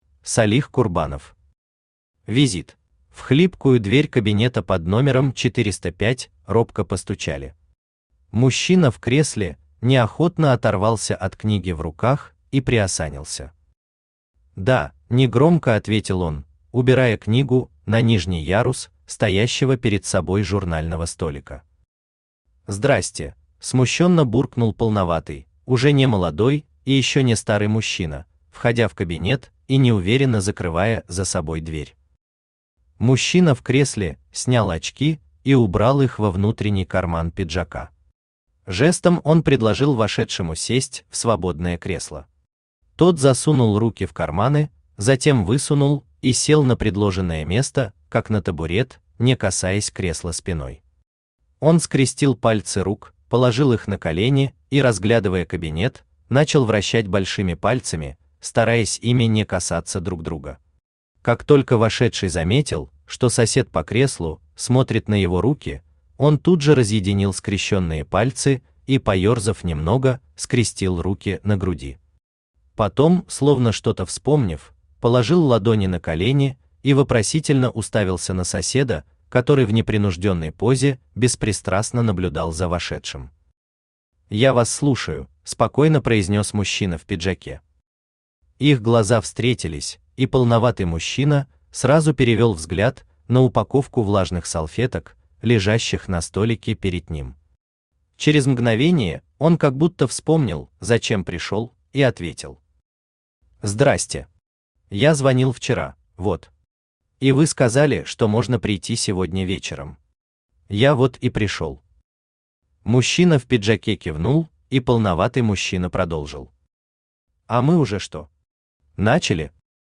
Аудиокнига Визит | Библиотека аудиокниг
Aудиокнига Визит Автор Салих Магомедович Курбанов Читает аудиокнигу Авточтец ЛитРес.